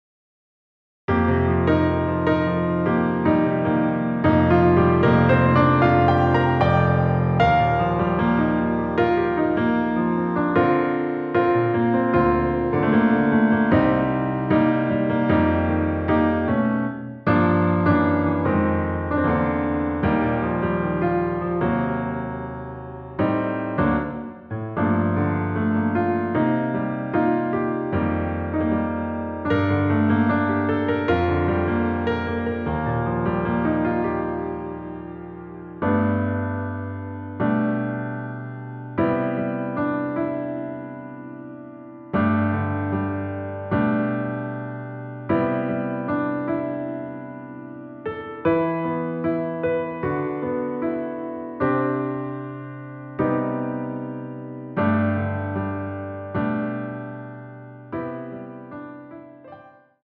처음 시작을 후렴구로 시작을 하고 앞부분 “드릴것이 없었기에 ~ 기억도 나지 않네요” 삭제된 편곡 입니다.
◈ 곡명 옆 (-1)은 반음 내림, (+1)은 반음 올림 입니다.
앞부분30초, 뒷부분30초씩 편집해서 올려 드리고 있습니다.
중간에 음이 끈어지고 다시 나오는 이유는